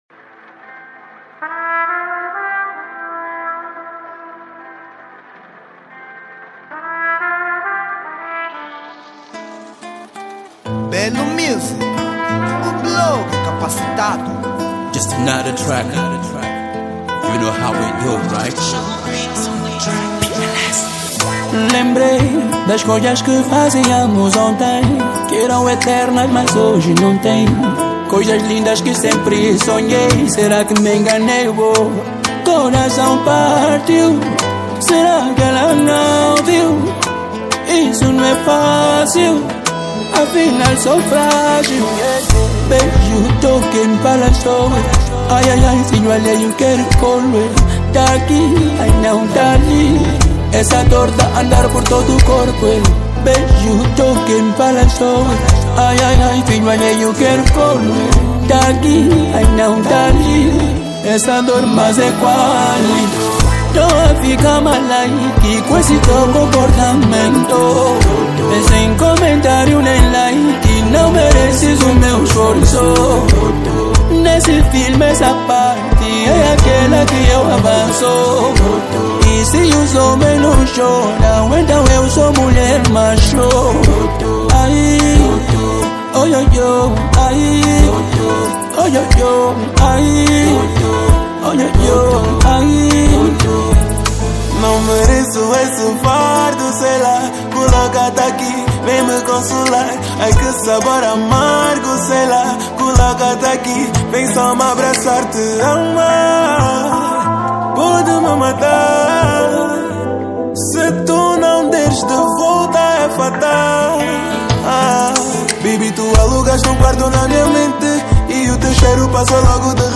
Género : Kizomba